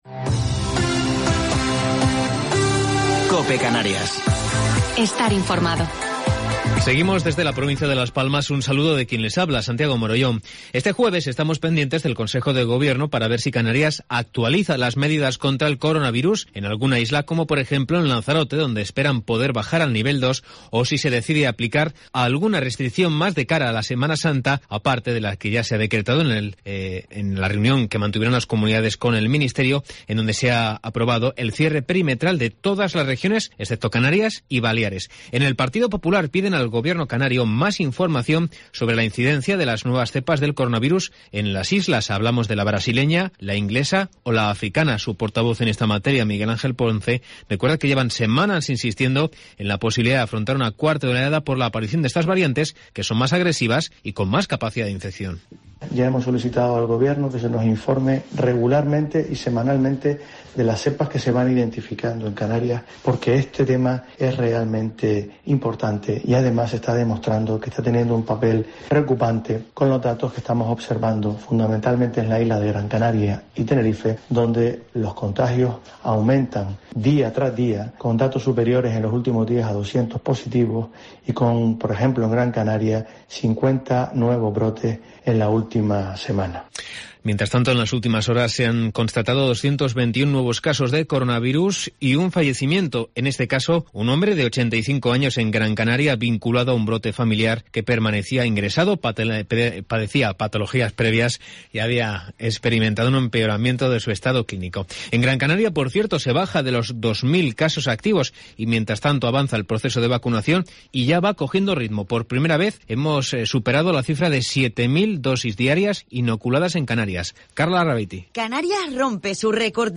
Informativo local 11 de Marzo del 2021